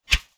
Close Combat Swing Sound 41.wav